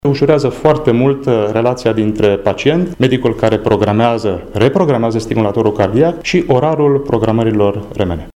Despre avantajele acestei alternative medicale ne-a vorbit medicul primar cardiolog